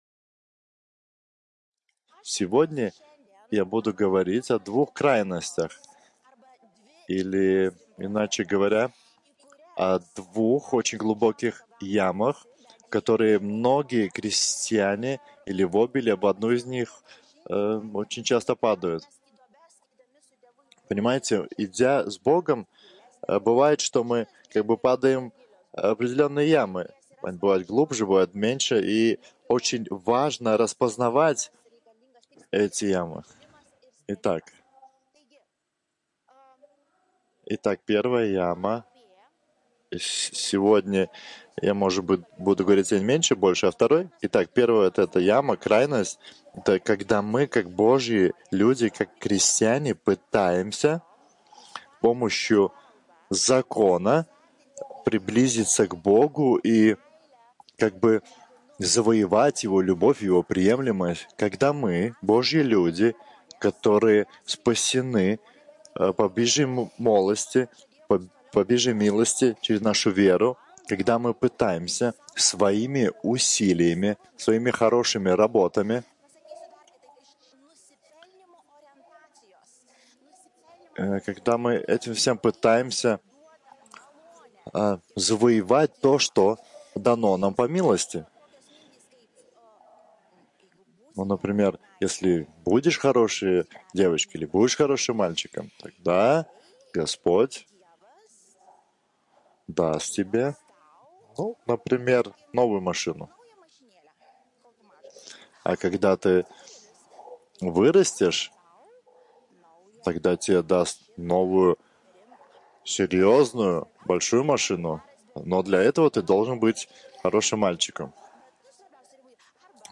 PAMOKSLO ĮRAŠAS MP3